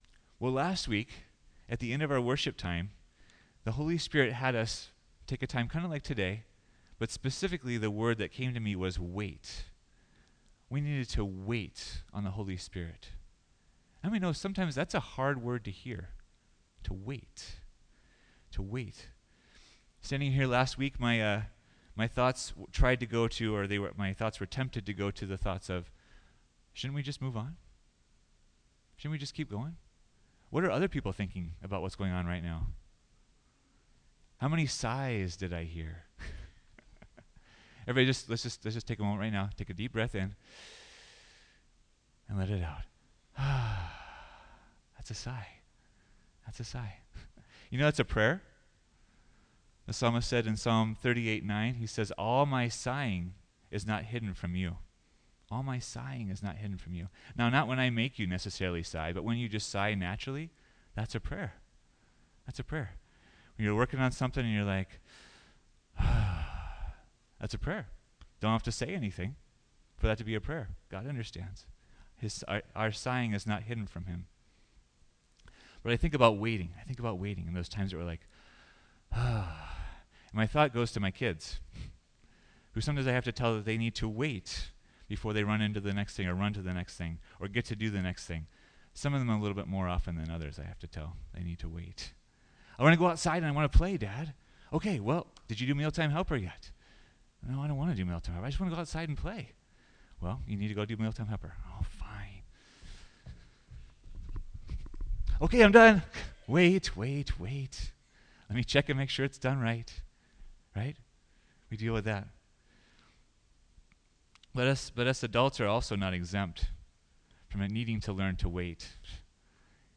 2023 Wait for the Gift Preacher